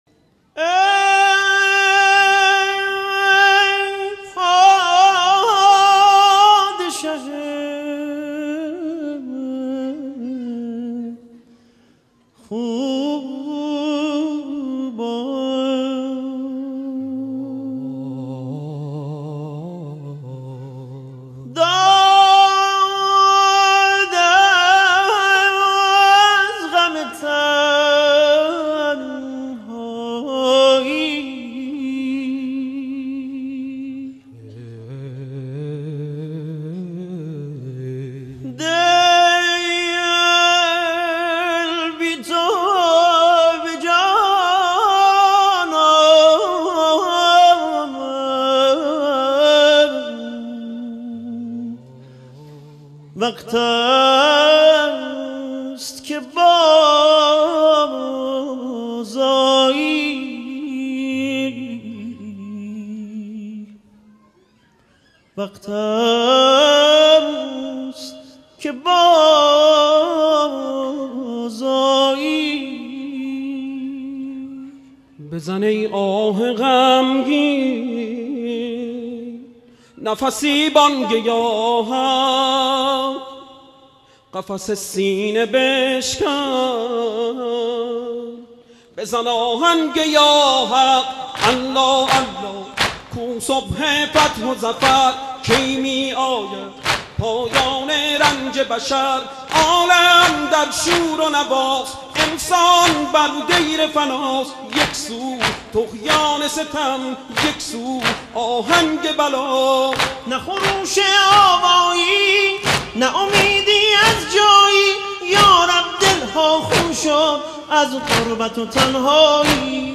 هیئت کوچه بیوک یزد
حسینیه گازرگاه محرم 93
در این تکه فیلم دو نوحه‌خوان یزدی در میان هیأتی بزرگ با یکدیگر هم‌آوا می‌خوانند: «الله الله، فریاد از جور زمان/ الله الله، فریاد از اهرمنان»، «الله الله کو صبح فتح و ظفر/ الله الله کِی می‌آید پایان رنج بشر».